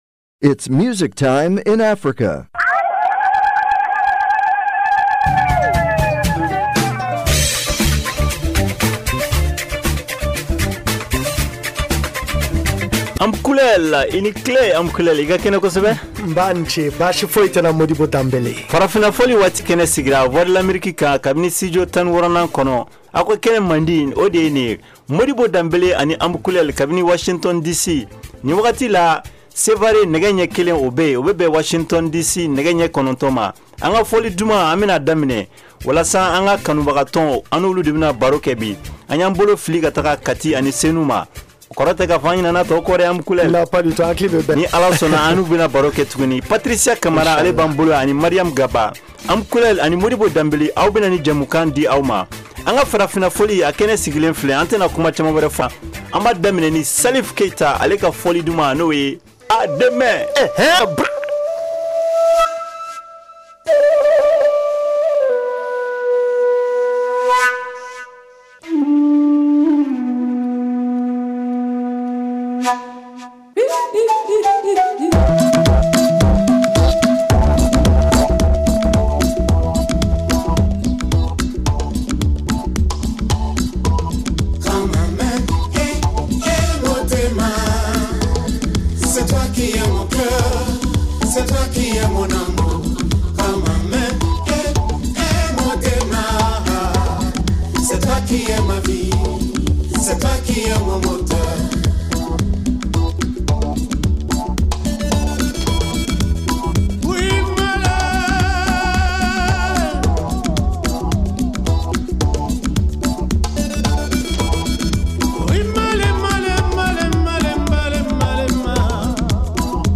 Farafina Fɔli Waati est une émission culturelle et musicale interactive en Bambara de la VOA.